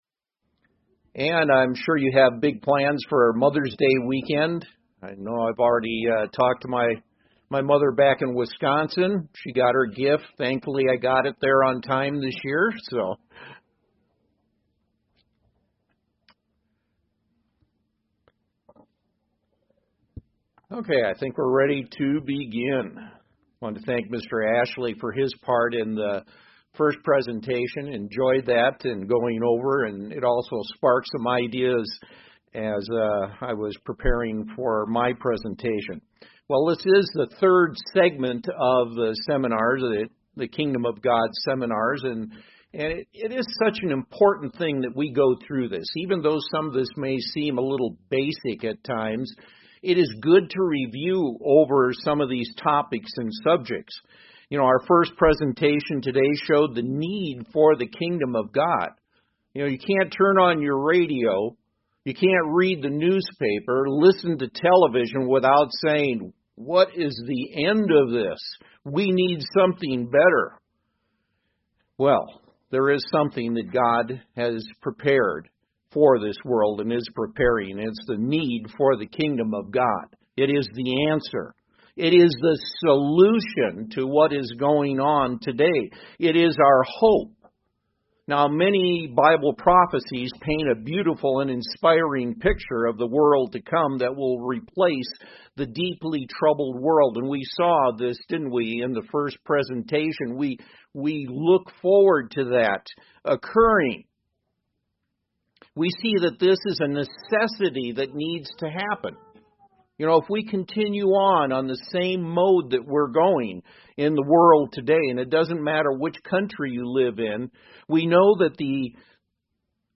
Learn six points to identiying true repentance in this Kingdom of God seminar.